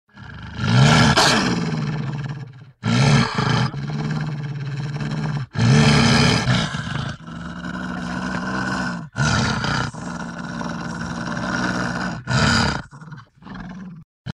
Звуки красного волка
Рык волка